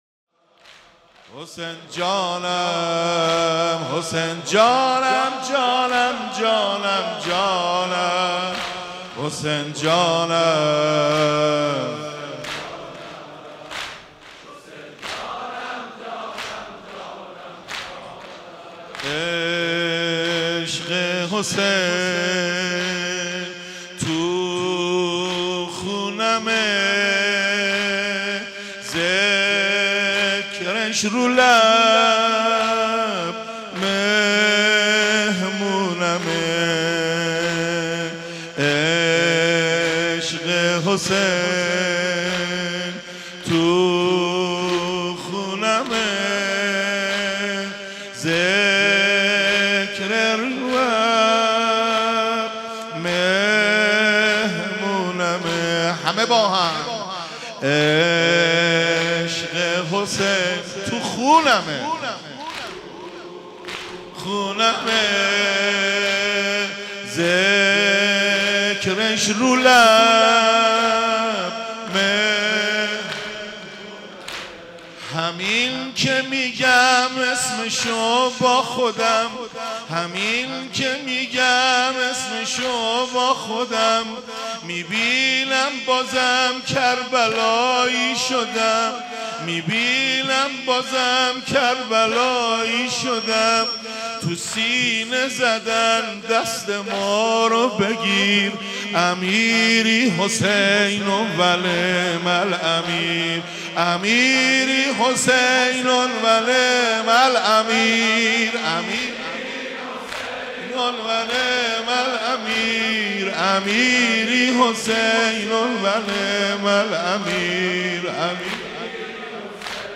مراسم شب اول محرم الحرام سال 1395